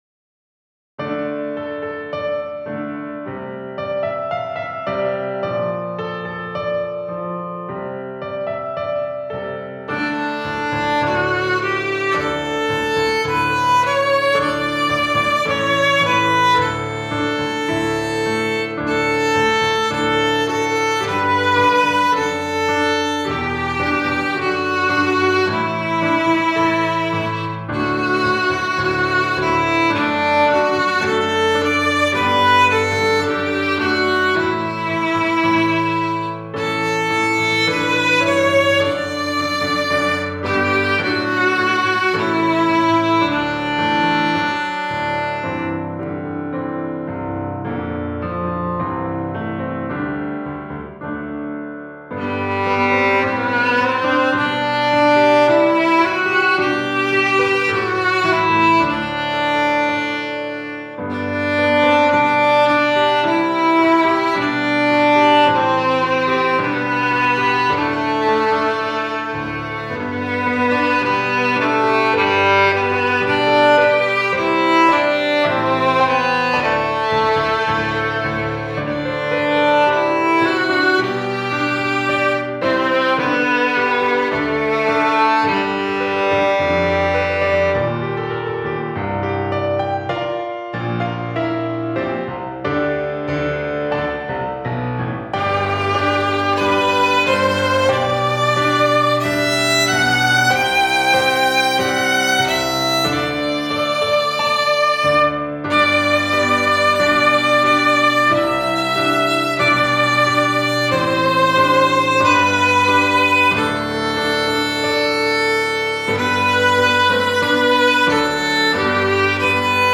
Arrangement for beginning string players
Instrumentation: Violin, Viola, Cello, Bass, Piano
This upbeat arrangement
Then the final stanza is an octave higher in the key of G.